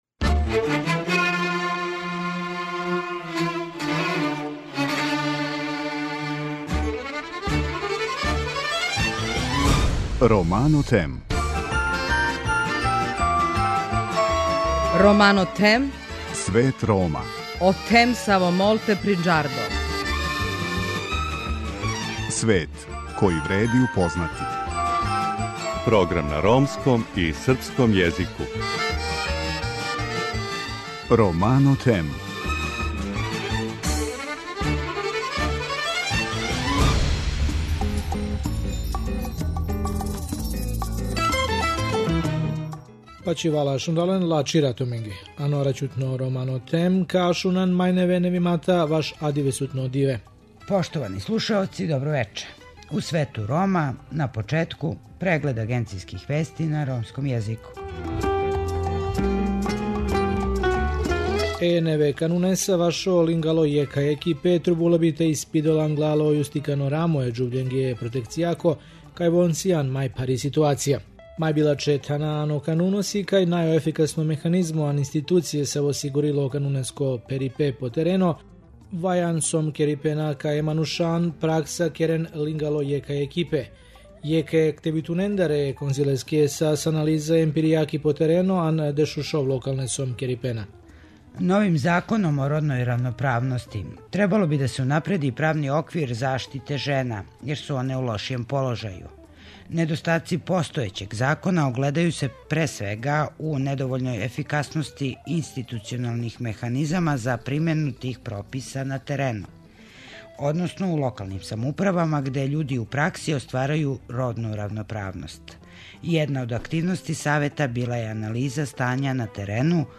Емисију воде